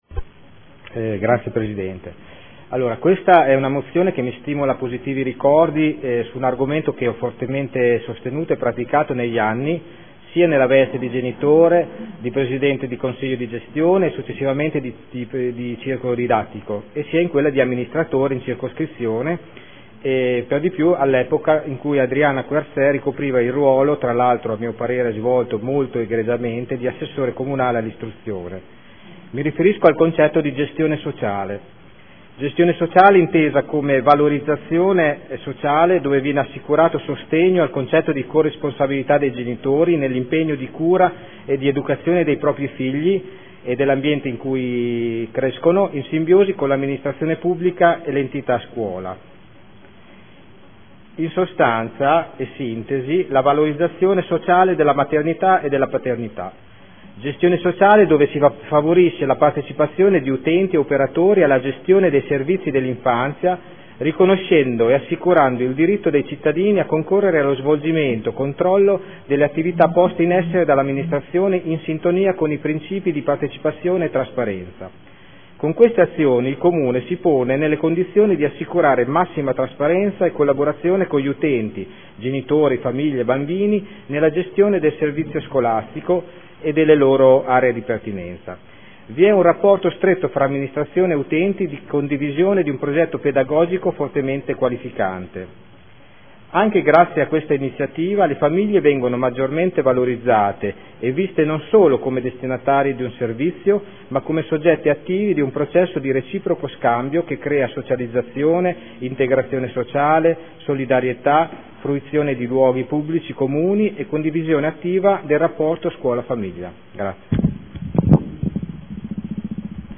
Vincenzo Walter Stella — Sito Audio Consiglio Comunale